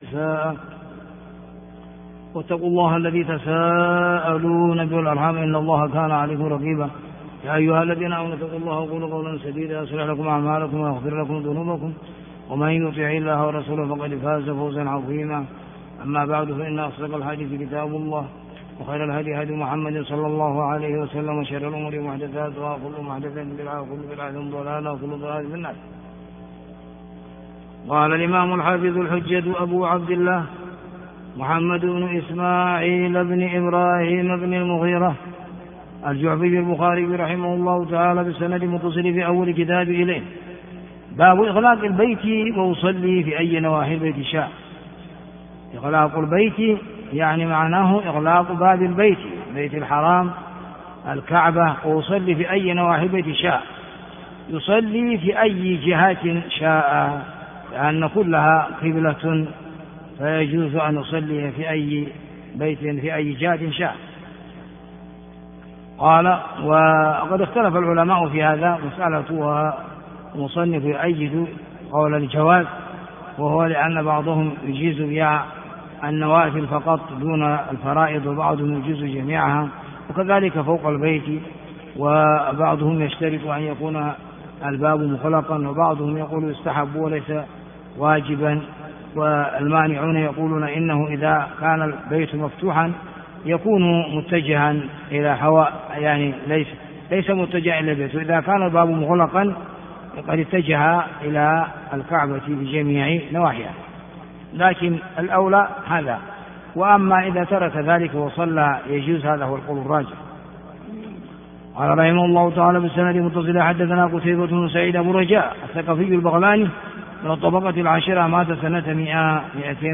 شرح صوتي لصحيح الإمام البخاري رحمه الله